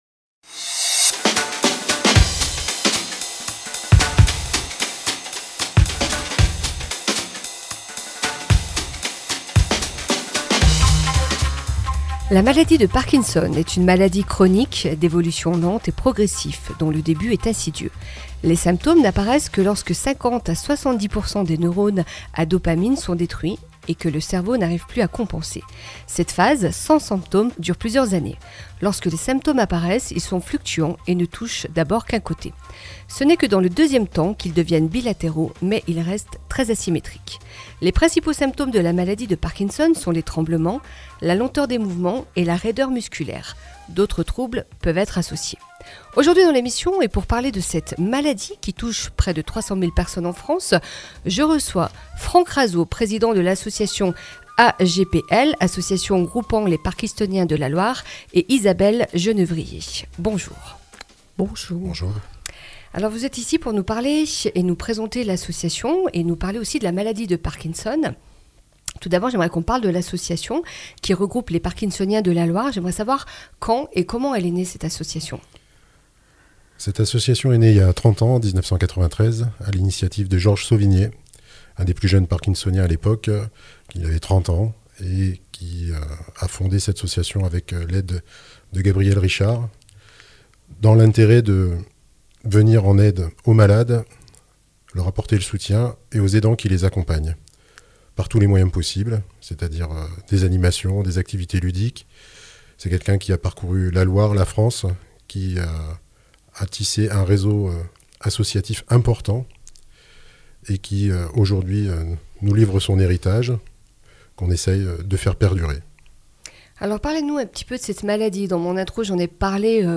nous recevons aujourd’hui à 11h30, sur Radio Ondaine, 90.9 Fm